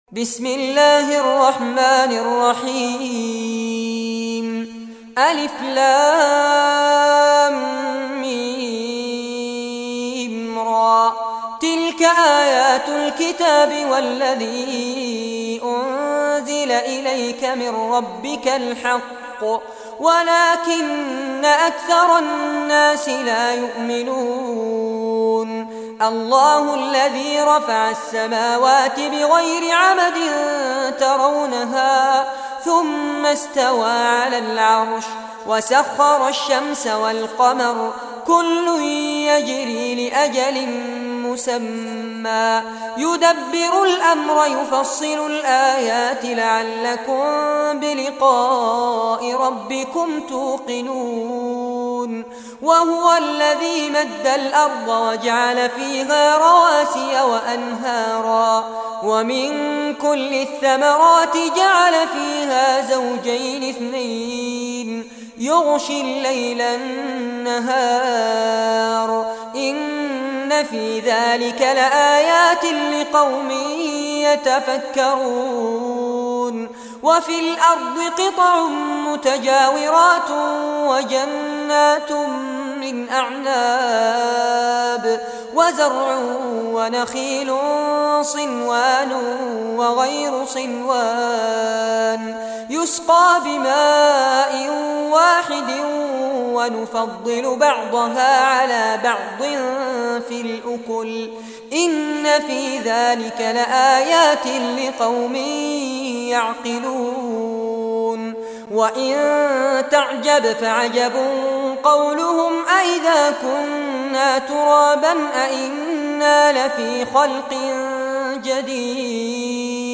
Surah Ar-Raad Recitation by Sheikh Fares Abbad
Surah Ar-Raad, listen or play online mp3 tilawat / recitation in Arabic in the beautiful voice of Sheikh Fares Abbad.
13-surah-raad.mp3